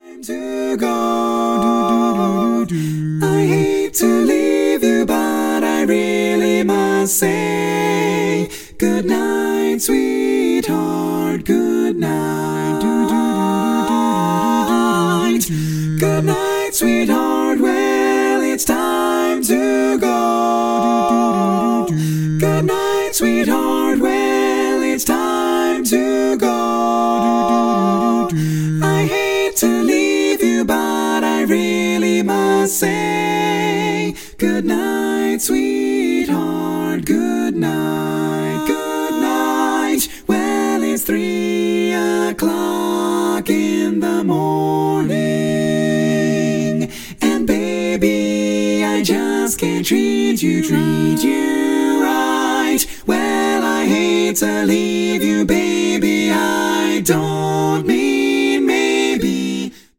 Full mix only
Category: Female